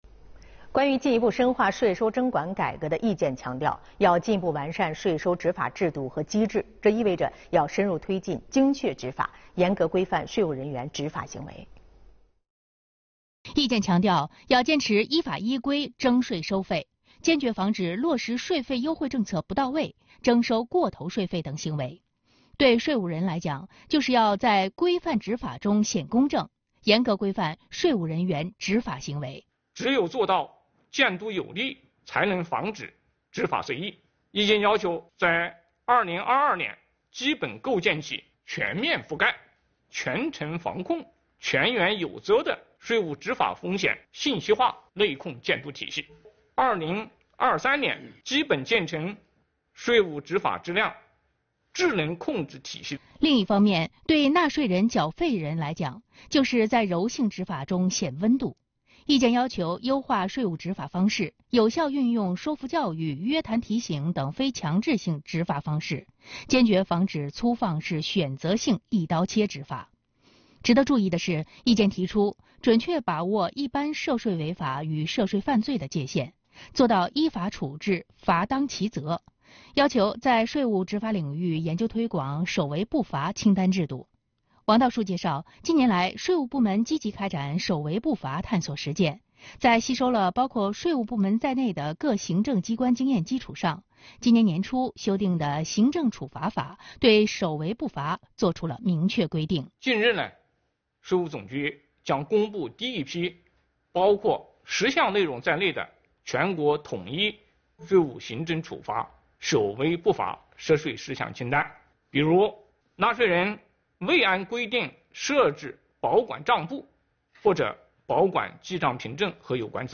视频来源：央视《新闻直播间》